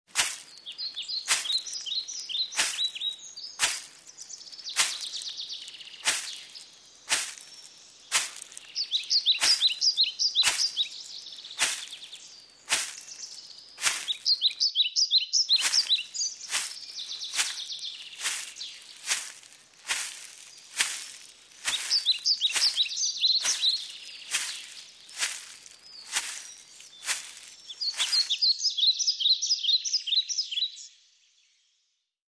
Звуки шагов в лесу
• Качество: высокое
Скачивайте реалистичные записи шагов по мягкой траве, хрустящему осеннему ковру из листьев и утоптанным земляным тропам.